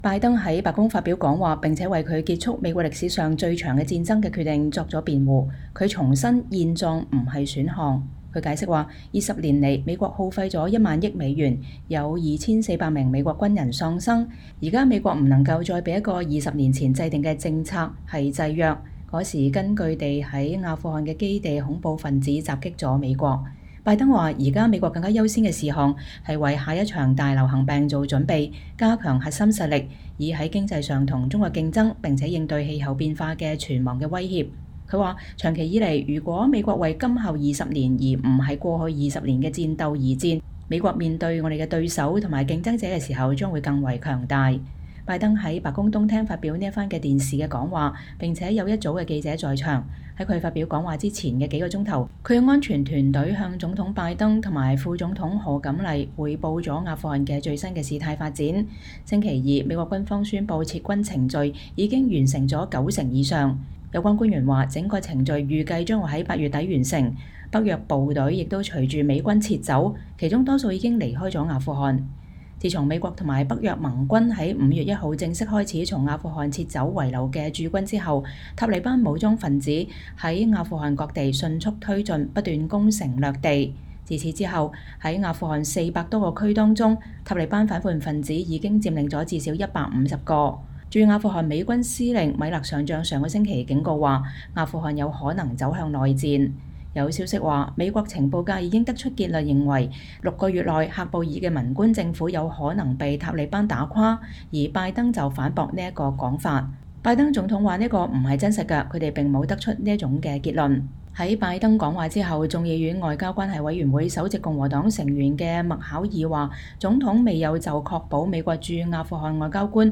拜登在白宮東廳發表了這番電視講話，並有一組記者在場。